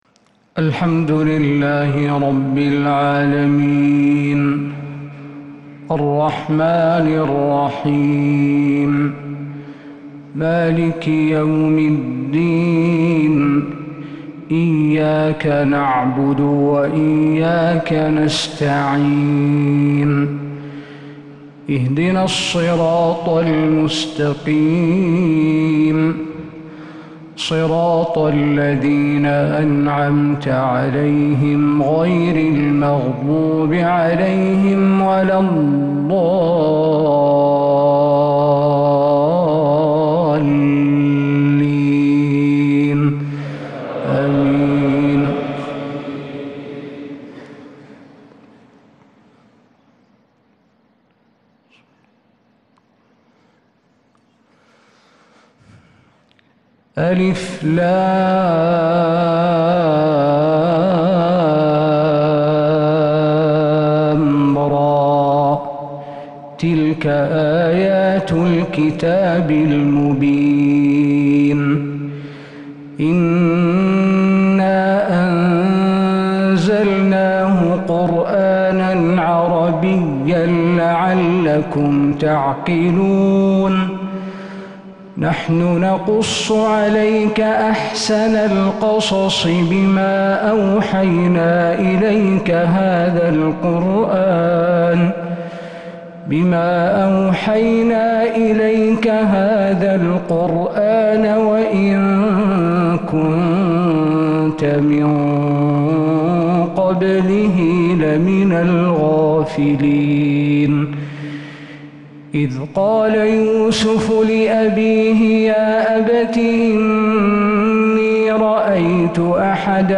فجر الأحد 9 صفر 1447هـ | فواتح سورة يوسف 1-21 | Fajr prayer from surat Yusuf 3-8-2025 > 1447 🕌 > الفروض - تلاوات الحرمين